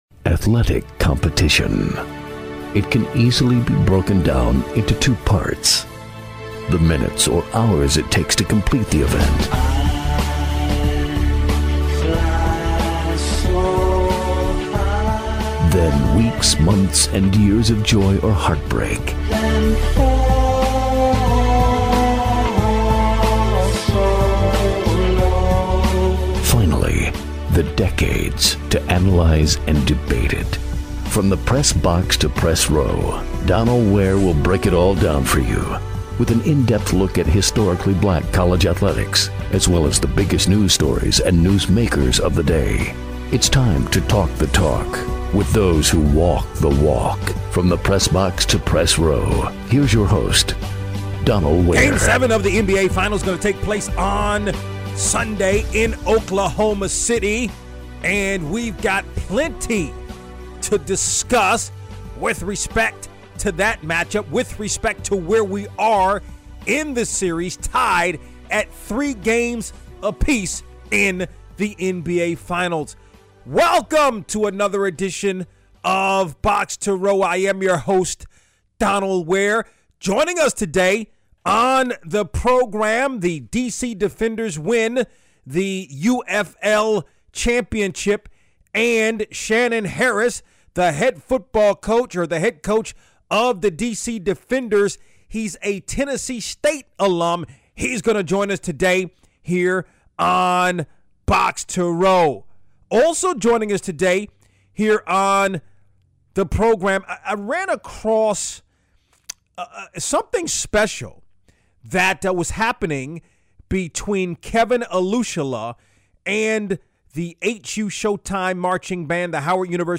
Interviews.